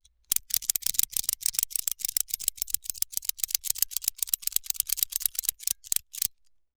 Part_Assembly_43.wav